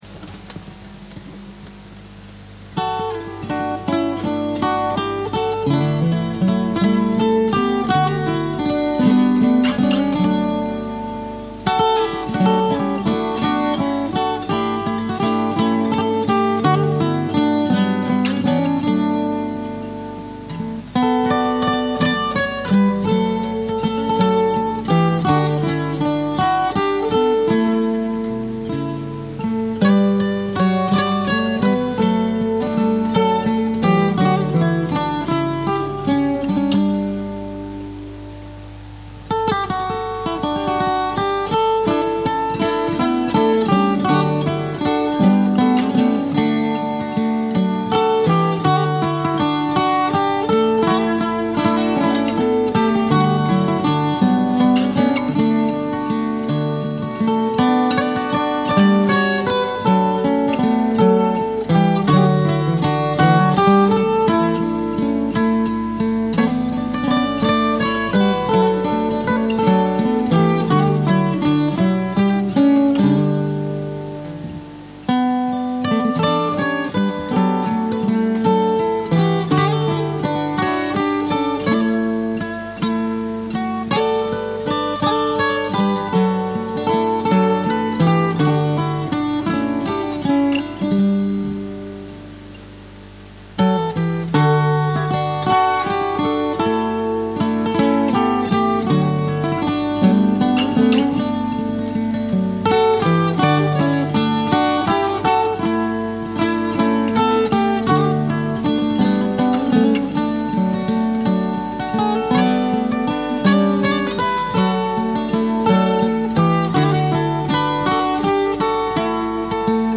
I may well vary the instrumentation for the ‘real’ version, but I quite like it with just guitar (though I’ve overdubbed here).
Instrumental